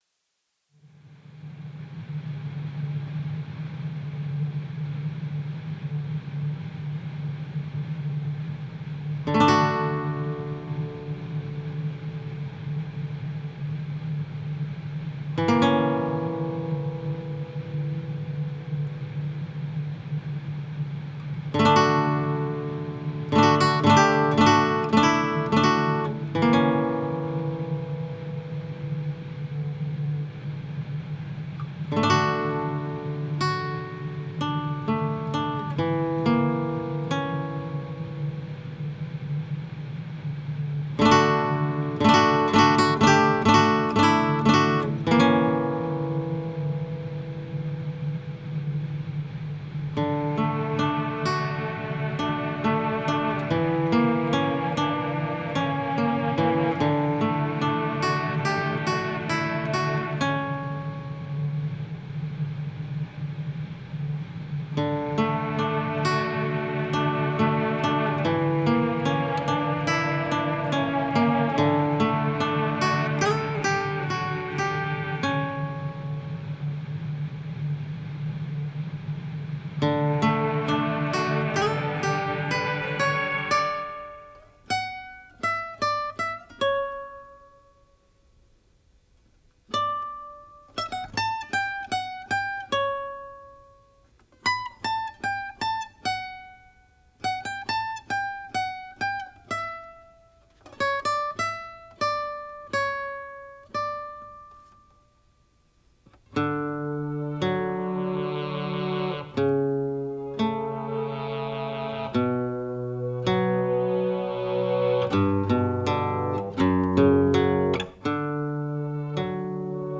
Genre: Post Rock.